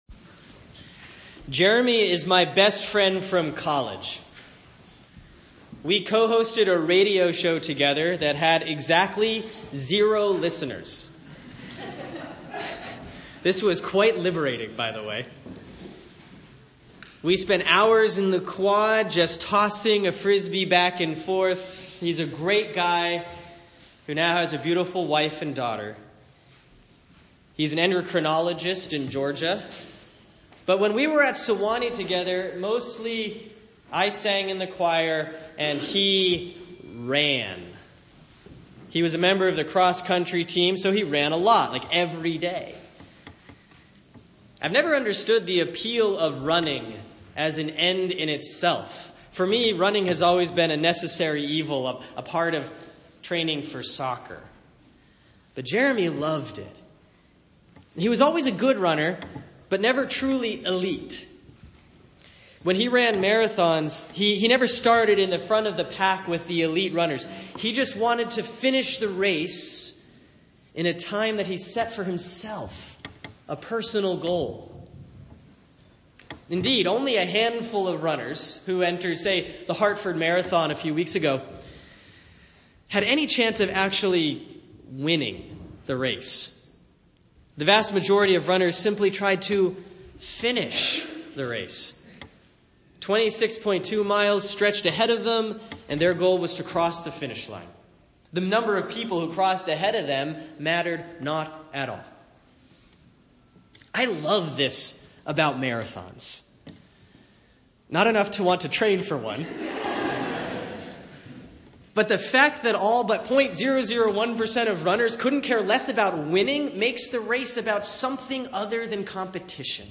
Sermon for Sunday, October 23, 2016 || Proper 25C || 2 Timothy 4:6-8, 16-18; Luke 18:9-14